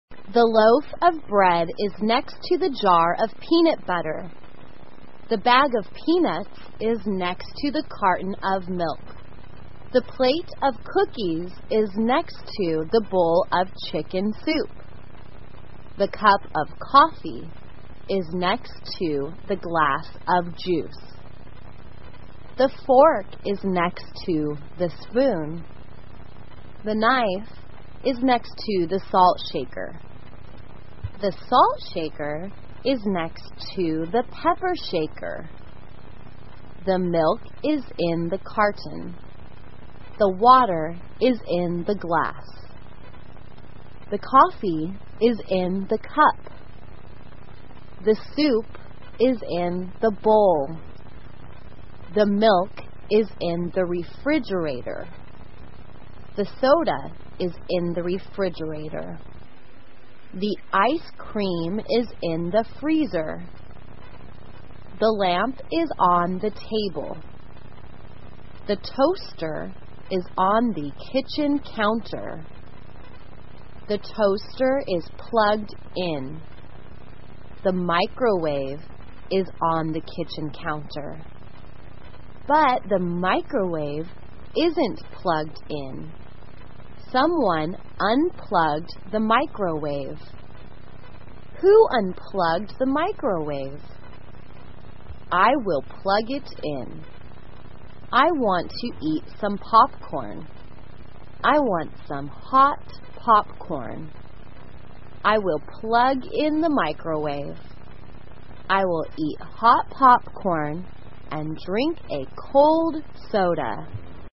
慢速英语短文听力 爆米花 听力文件下载—在线英语听力室